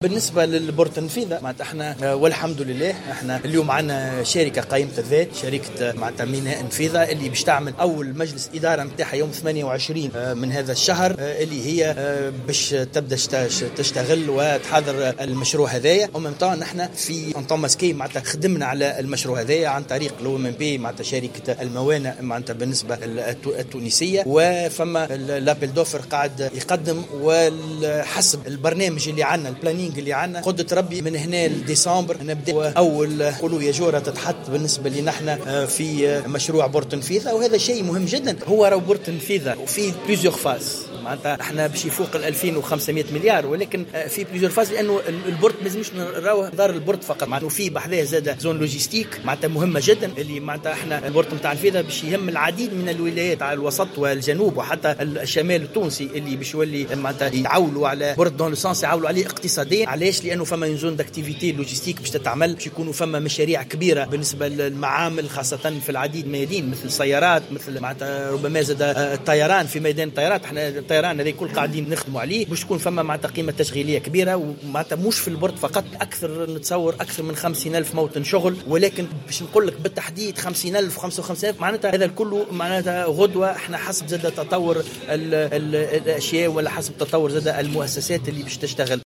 أكّد وزير النقل هشام بن أحمد في تصريح لمراسلة الجوهرة اف ام تقدم مشروع ميناء المياه العميقة بالنفيضة، لافتا إلى أن شركة الميناء ستعقد أول مجلس إدارة لها يوم 28 فيفري الجاري للانطلاق في الأشغال.